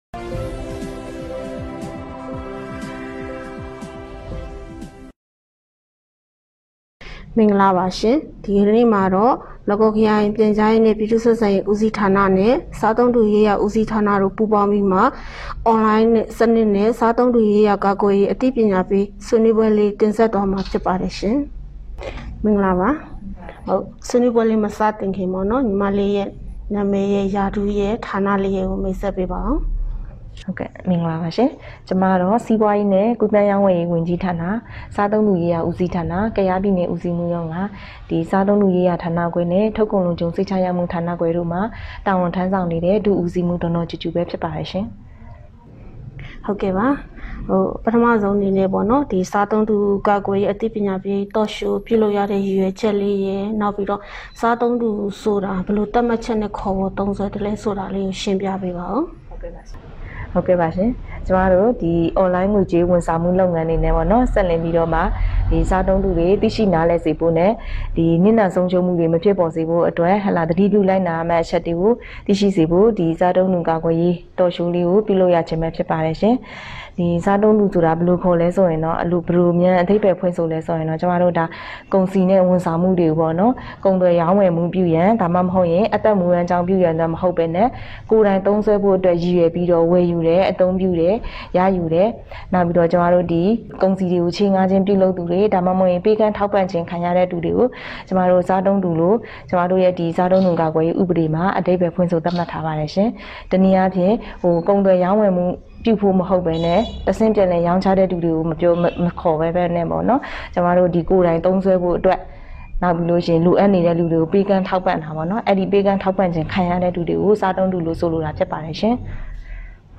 Online စနစ်ဖြင့် စားသုံးသူကာကွယ်ရေးအသိပညာပေး Talk Show ဆွေးနွေး